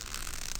Draw.wav